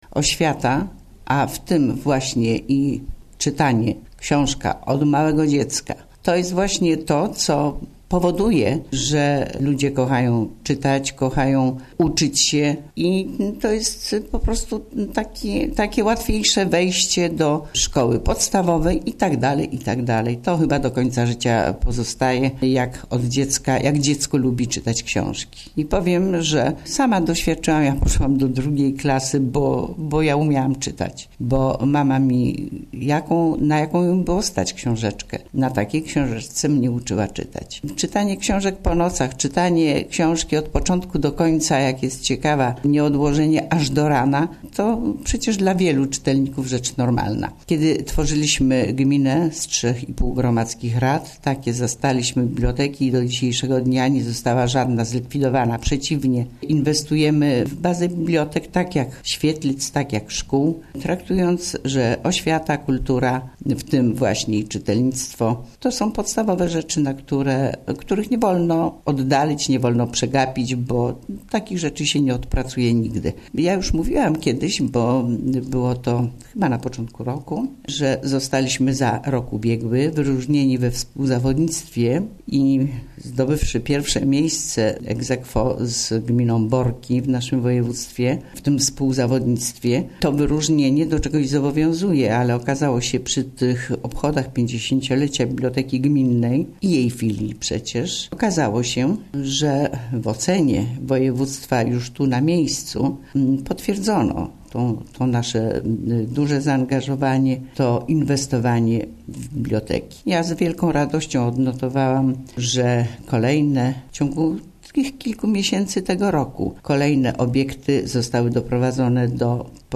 Wójt Gminy Łuków